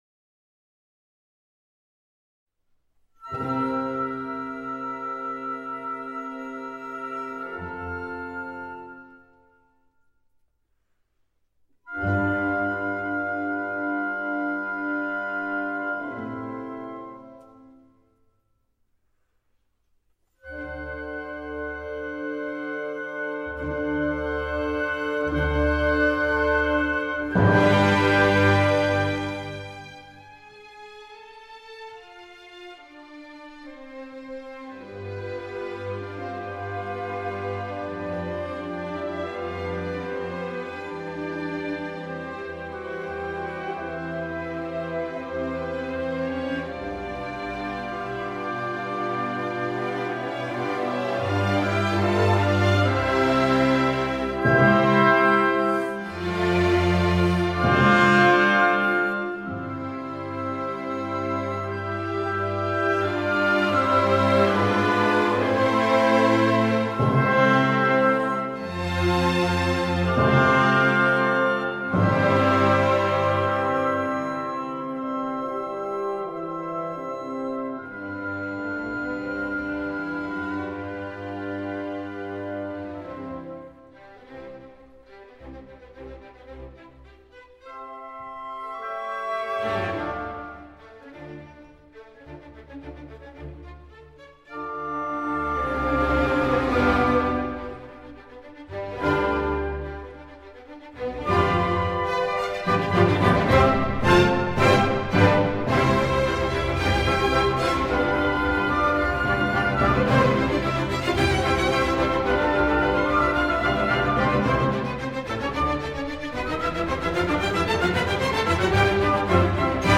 Beethoven Symphony 1 mvt 1 orchestral violin excerpt
Daniel Barenboim: Staatskapelle Berlin, 2000